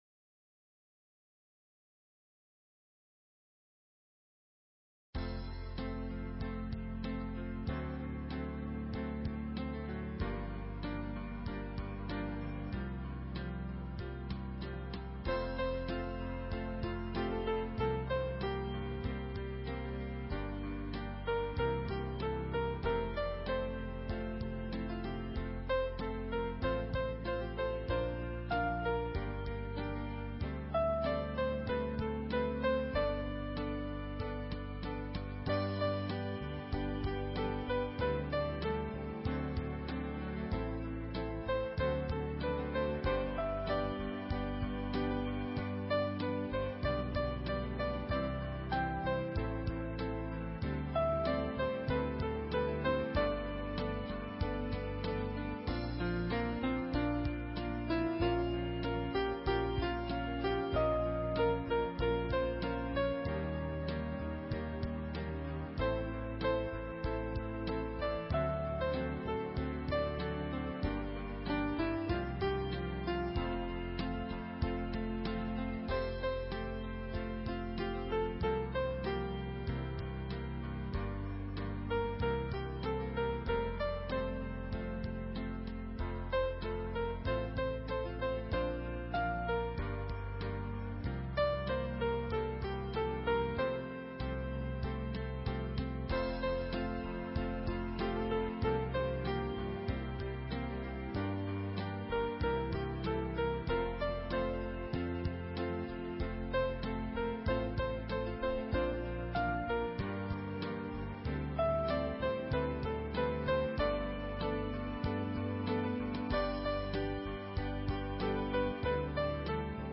Talk Show Episode, Audio Podcast, Peaceful_Planet and Courtesy of BBS Radio on , show guests , about , categorized as
Peaceful Planet Show WELCOMES Stanton Friedman, formost UFO authority in the world!